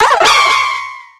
Audio / SE / Cries / VIRIZION.ogg